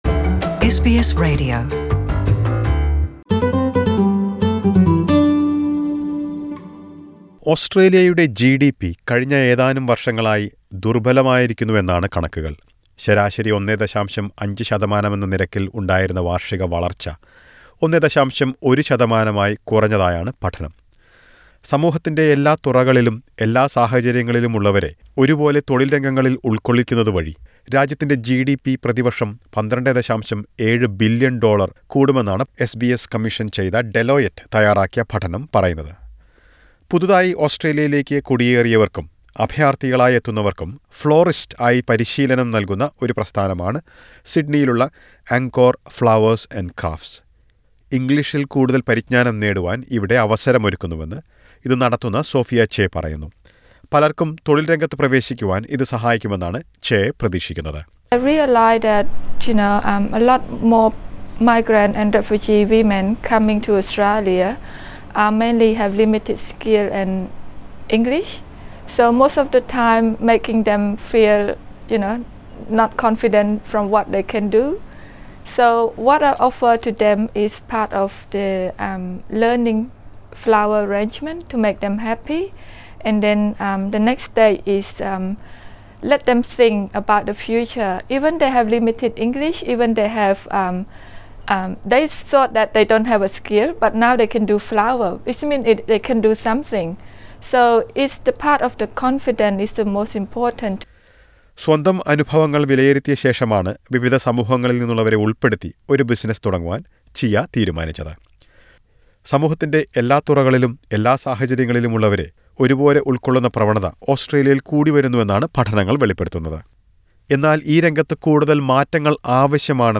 A new report by Deloitte, commissioned by SBS, argues that a stronger focus on social inclusion has the potential to lift the nation’s GDP by almost $12.7 billion a year. Listen to a report on this.